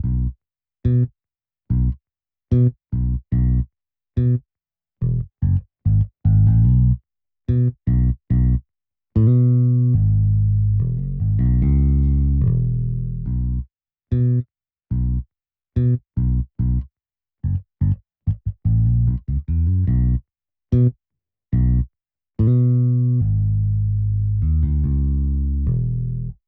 02 bass B.wav